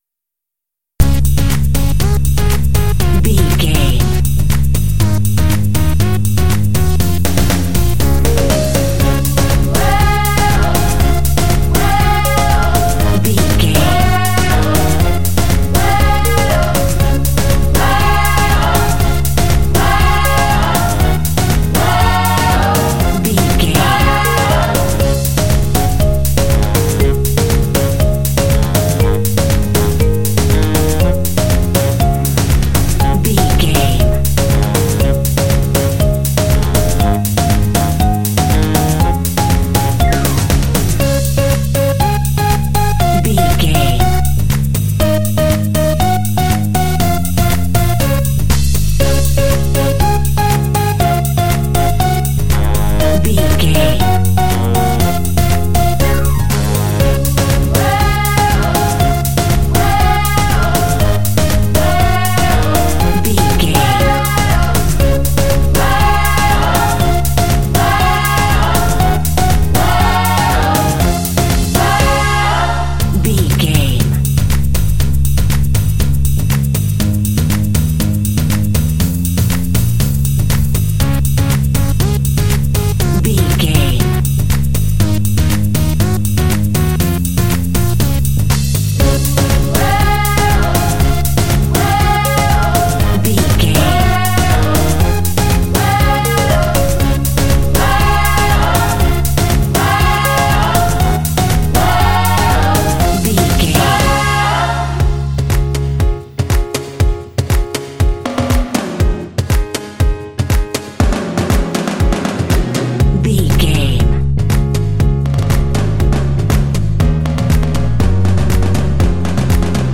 Uplifting
Ionian/Major
D♭
energetic
driving
synthesiser
drums
percussion
electric piano
saxophone
bass guitar
pop